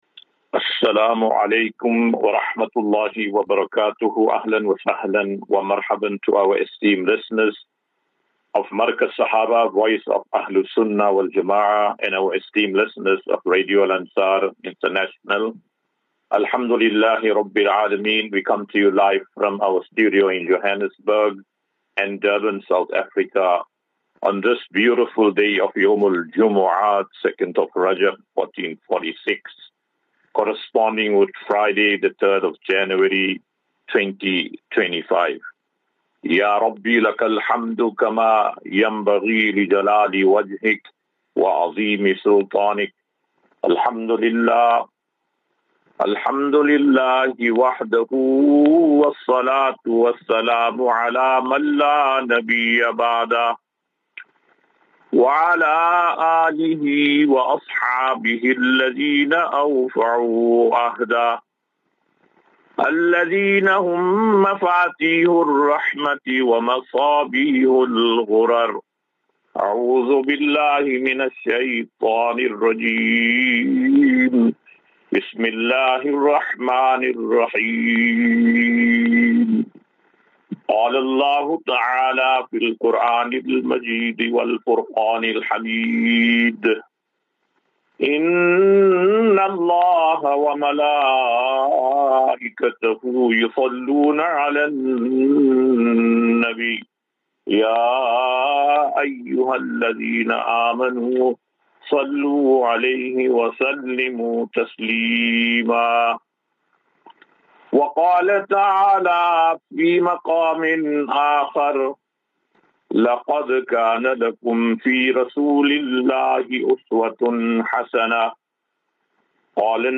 3 Jan 03 January 2025. Assafinatu - Illal - Jannah. QnA